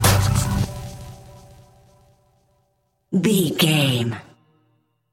Aeolian/Minor
synthesiser
drum machine
hip hop
Funk
neo soul
acid jazz
energetic
bouncy
funky